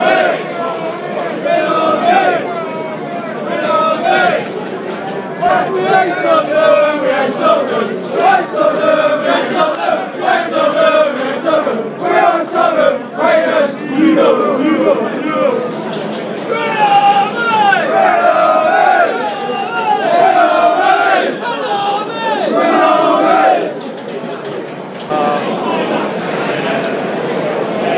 Atmosphere at the Grove
Share Facebook X Next Arsenal fans celebrate.